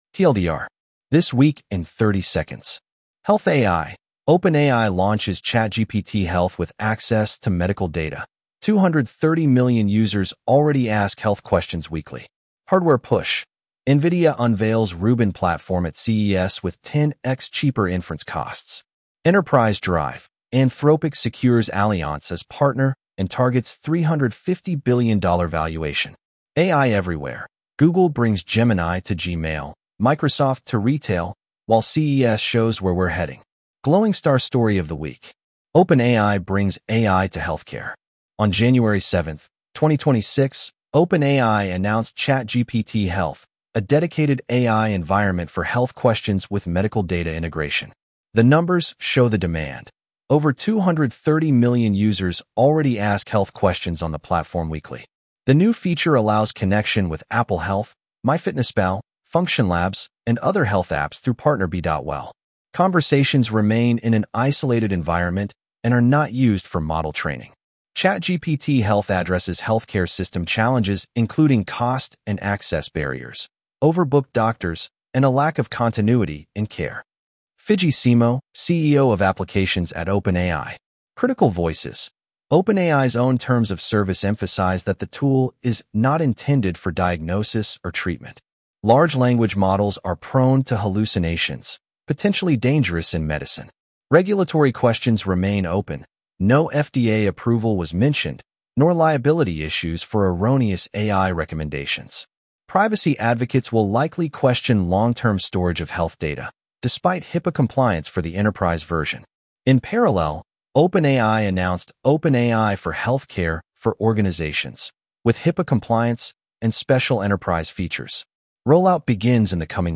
Read aloud with edge-tts (Microsoft Azure Neural Voice: en-US-AndrewNeural)